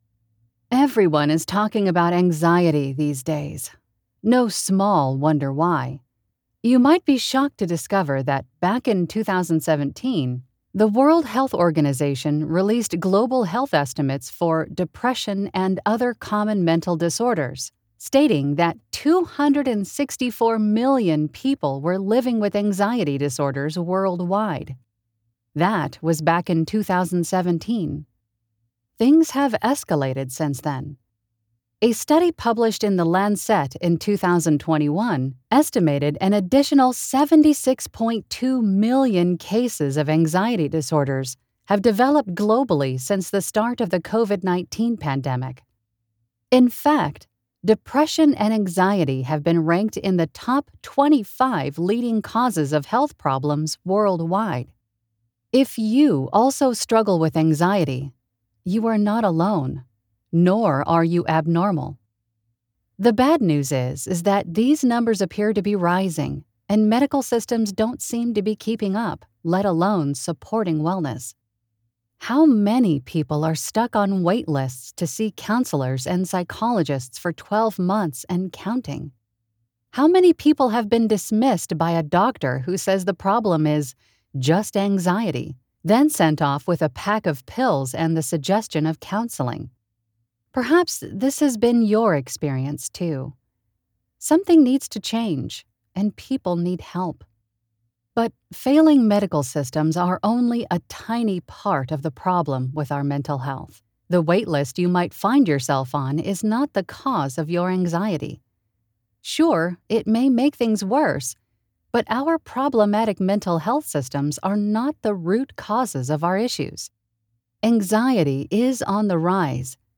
Warm, Natural and Experienced!
Self Help Audiobook
North American General